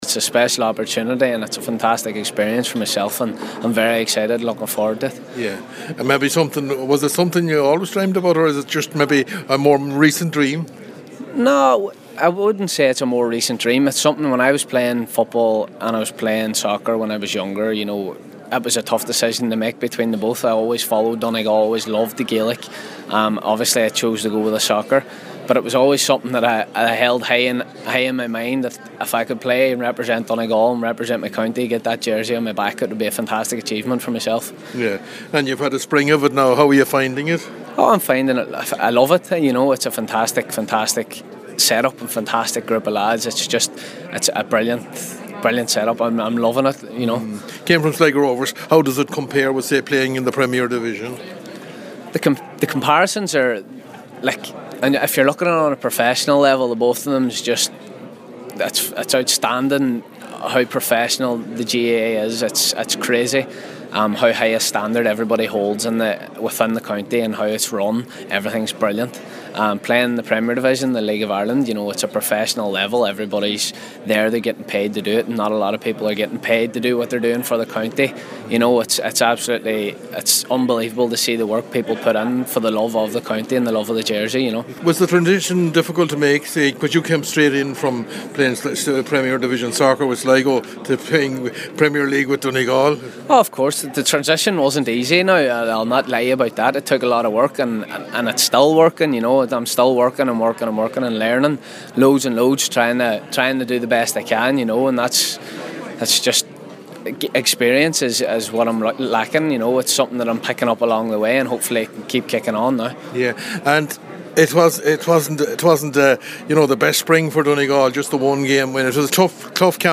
at the Ulster Championship launch on Monday evening